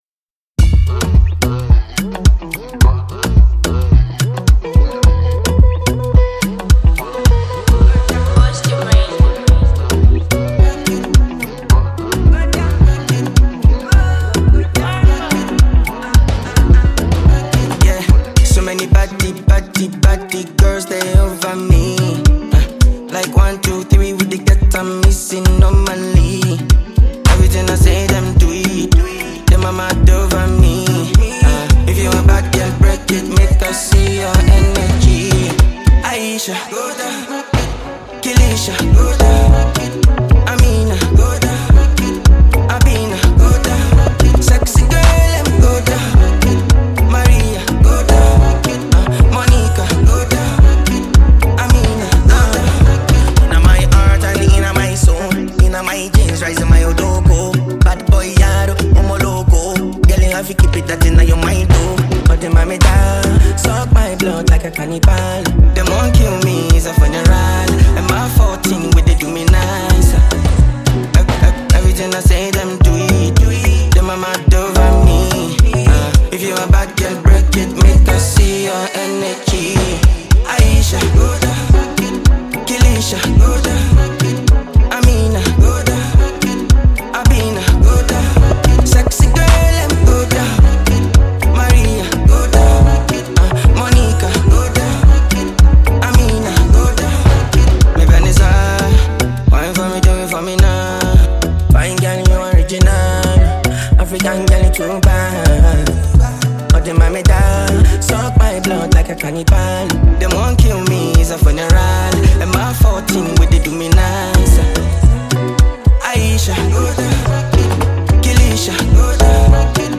Ghanaian afrobeat musician and songwriter